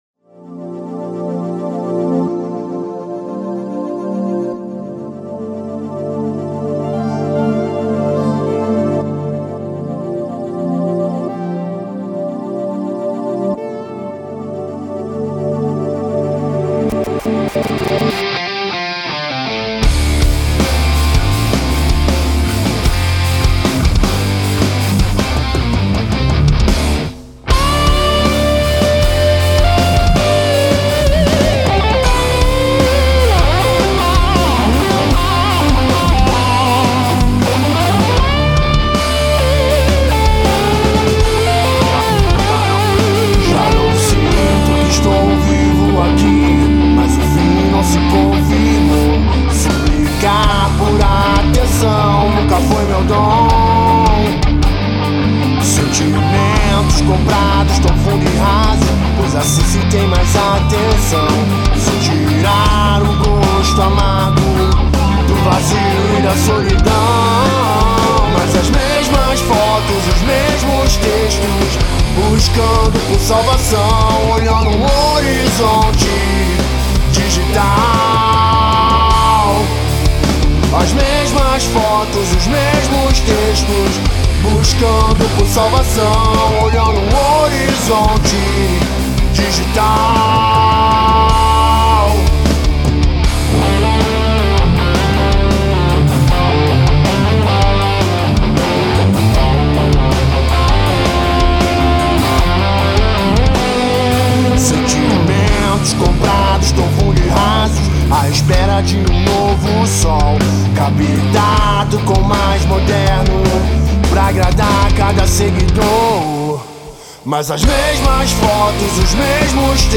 Vocalista
Guitarrista E Back Vocal